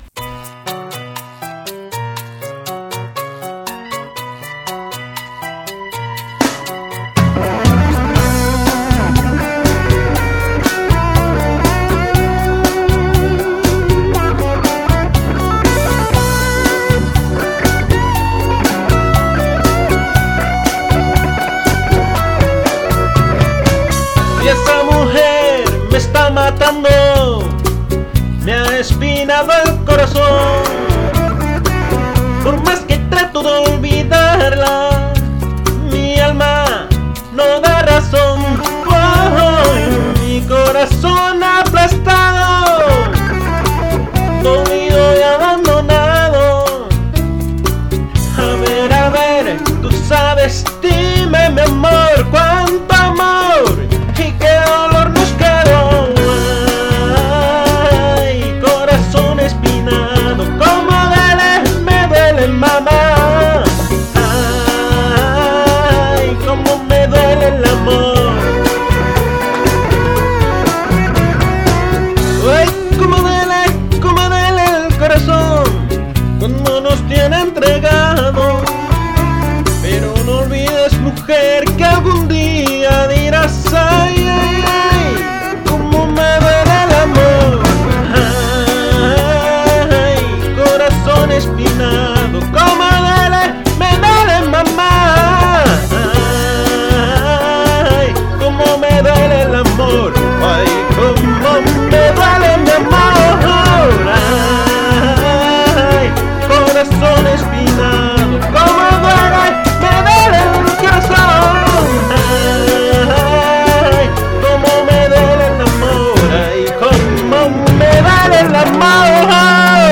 Me gusta cantar, pero sólo como aficionado.